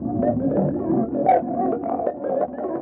RI_ArpegiFex_85-05.wav